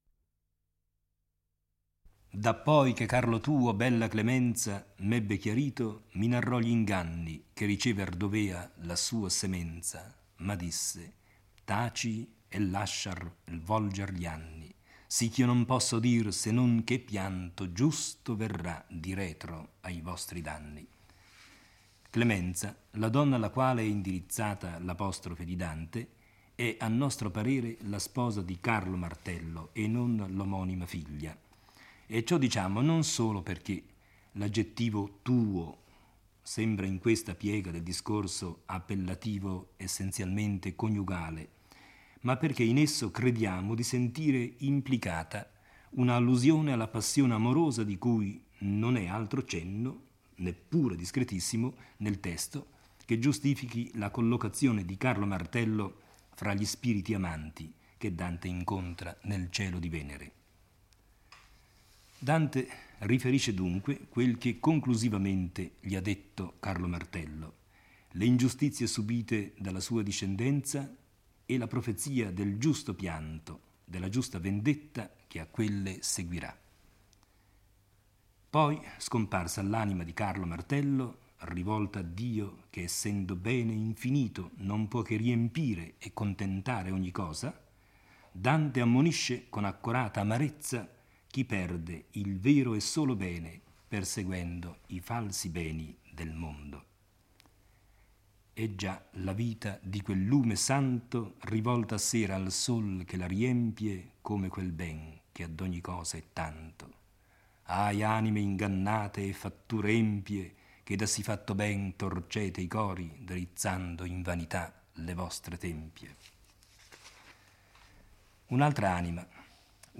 legge e commenta il IX canto del Paradiso